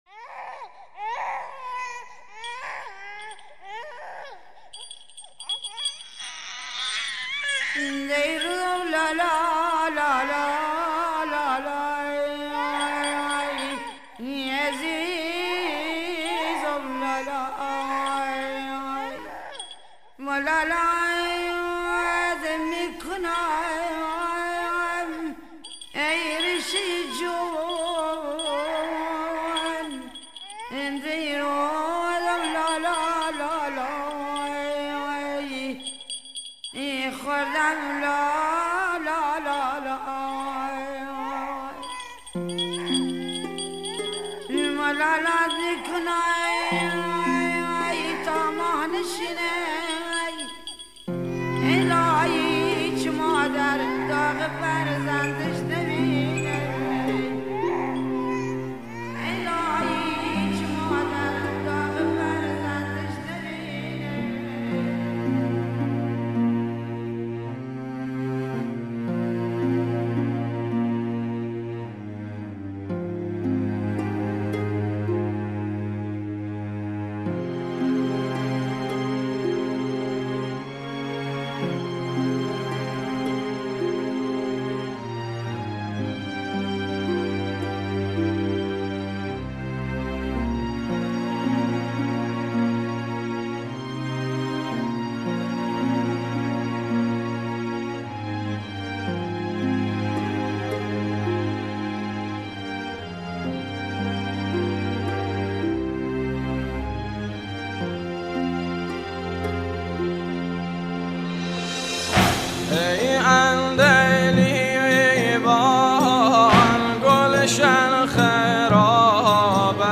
مداحی سوزناک
نوحه بوشهری دشتی غمگین